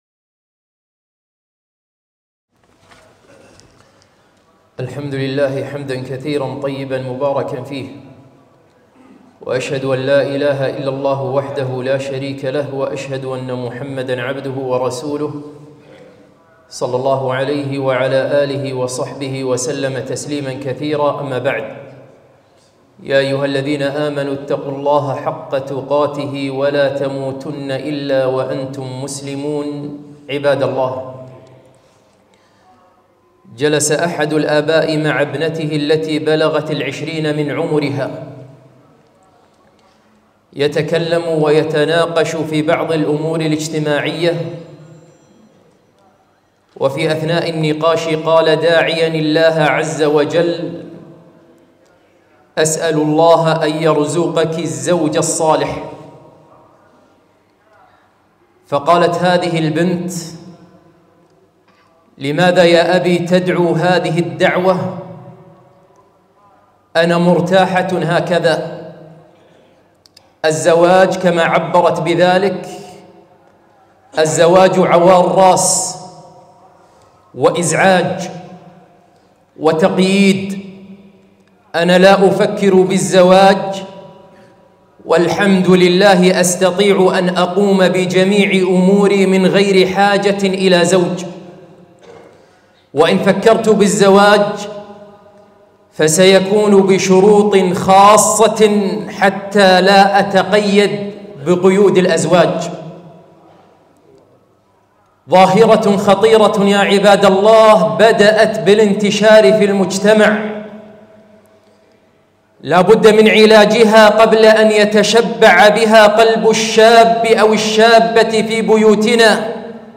خطبة - ابنتي لا تريد أن تتزوج!!